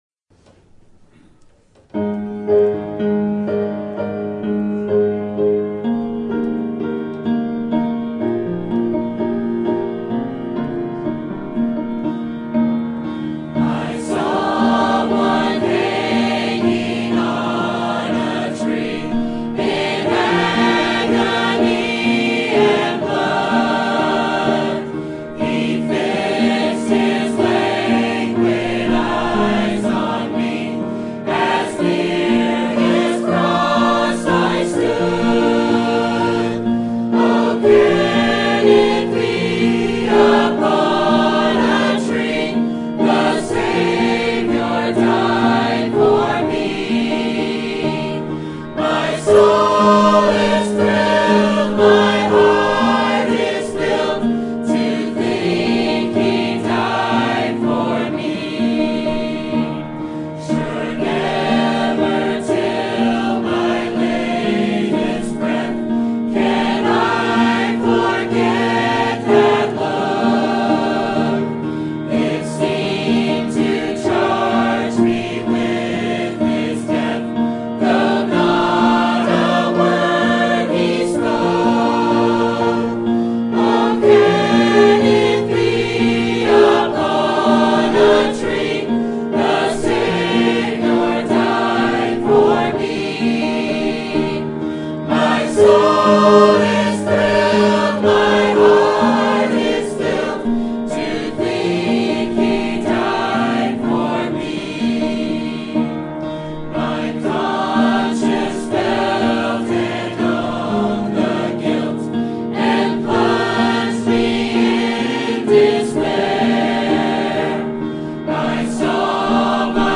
Sermon Topic: General Sermon Type: Service Sermon Audio: Sermon download: Download (25.2 MB) Sermon Tags: 1 Corinthians Resurrection Saviour Alive